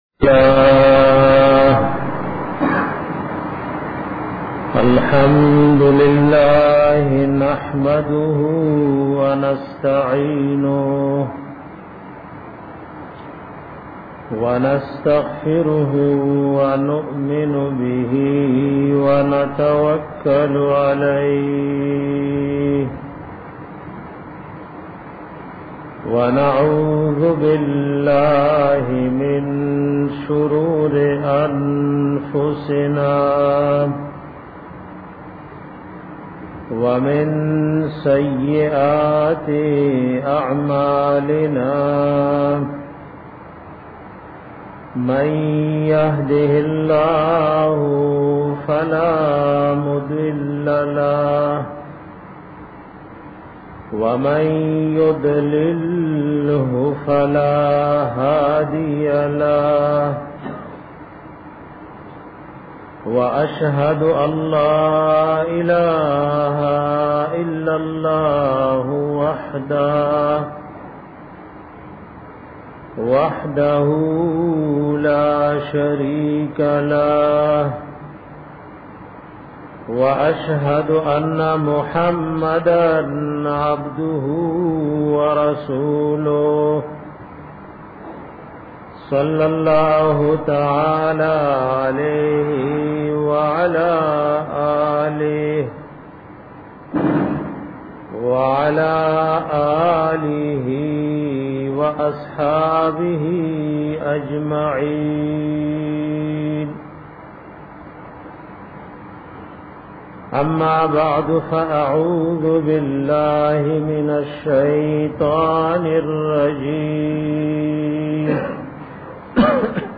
bayan pa bara da bazar ao magshray ka